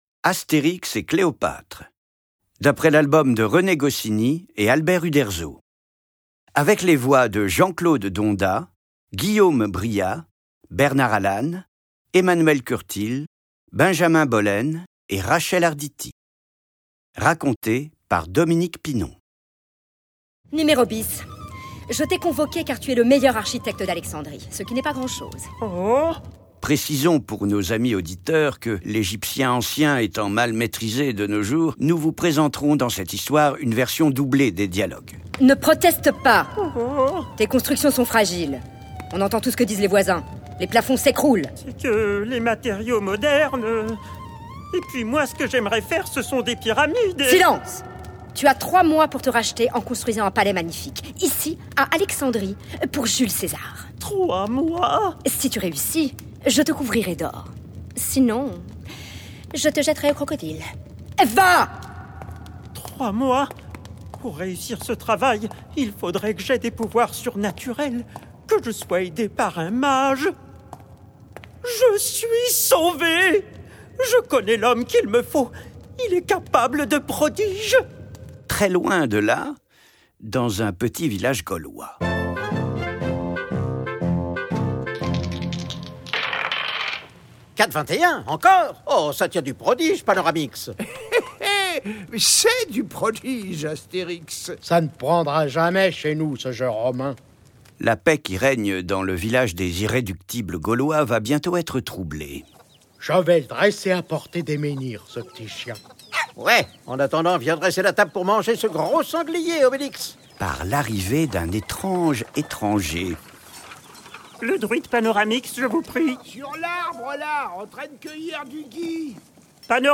Extrait gratuit - Astérix et Cléopatre de Jean-Claude Donda, René Goscinny, Dominique Pinon, Albert Uderzo